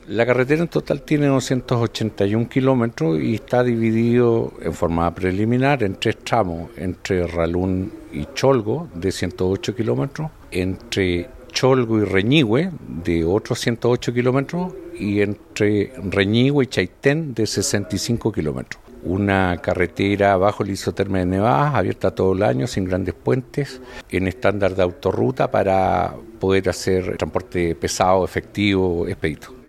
A los pies del Volcán Chaitén, en medio de la característica llovizna del sur, los gobernadores de la zona sur austral del país, firmaron su compromiso para trabajar en pos de la conectividad denominada “Chile por Chile”.